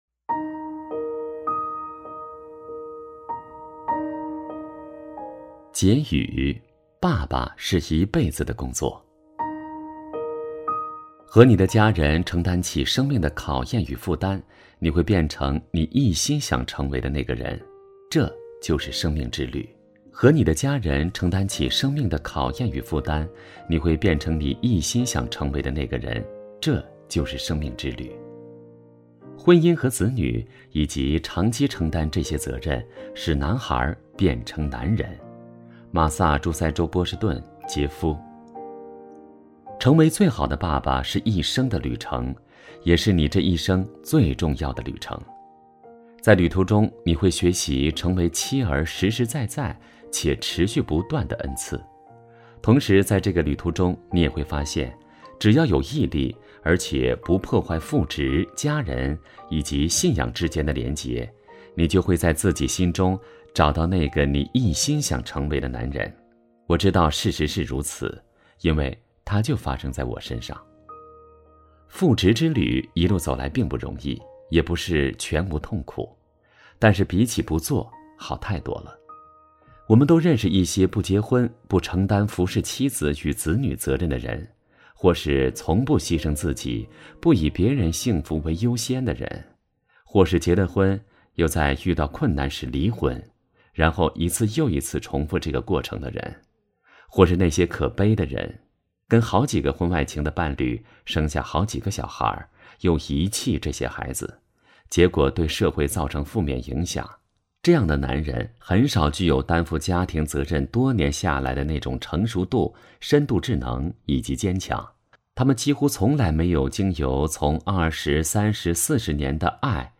首页 > 有声书 > 婚姻家庭 | 成就好爸爸 | 有声书 > 成就好爸爸：42 结语 爸爸是一辈子的工作